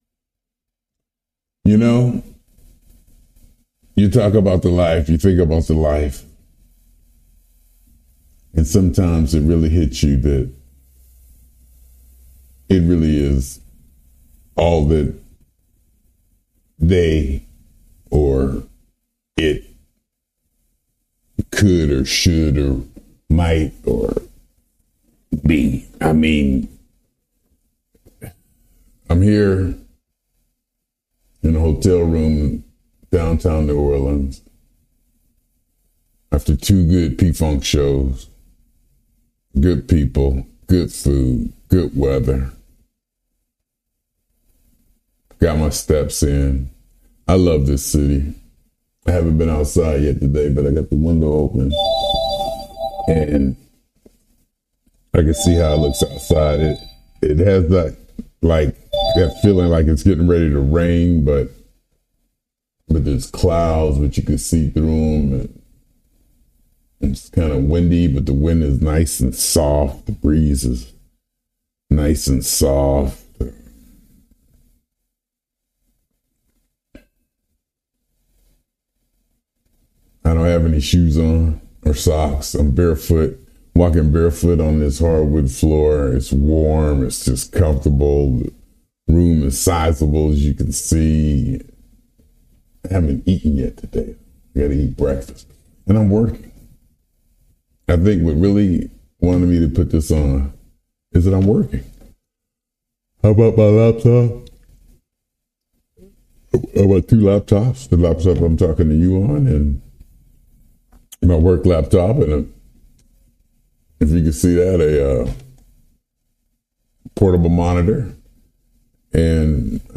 In this talk, the speaker shares his experiences in New Orleans, highlighting the joy of attending P Funk shows while managing work commitments.